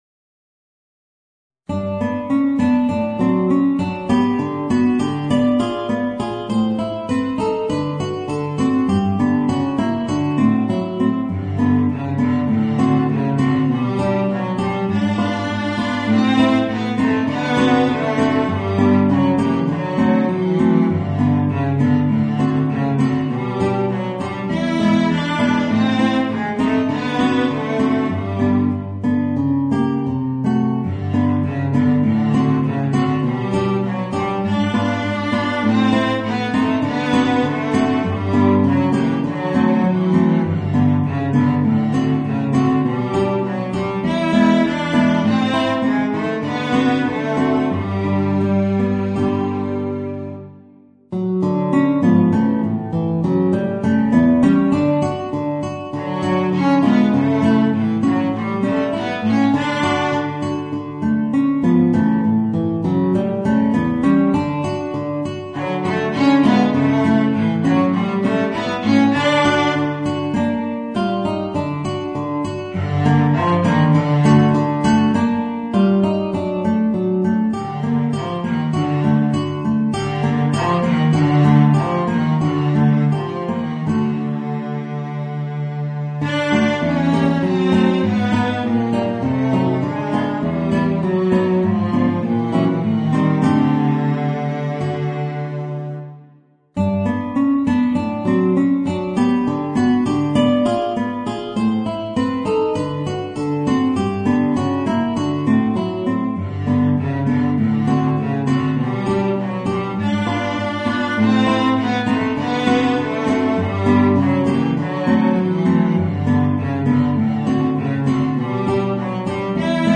Voicing: Violoncello and Guitar